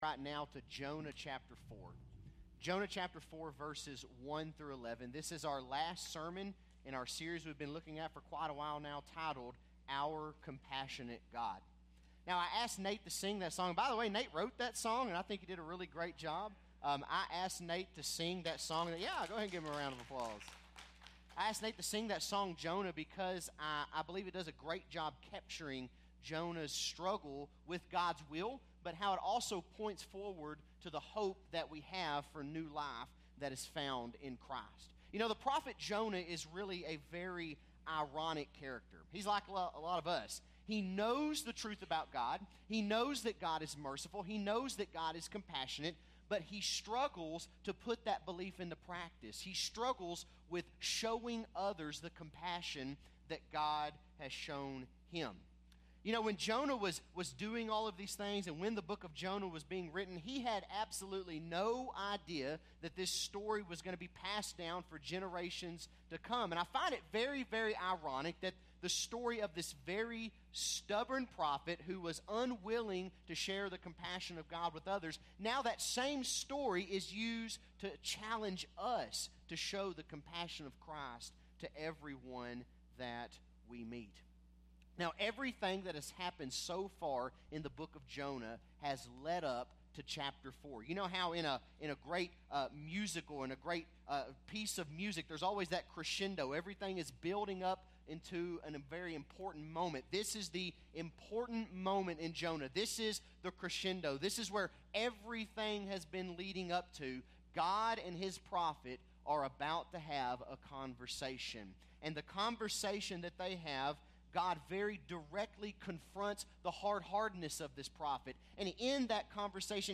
Bible Text: Jonah 4 | Preacher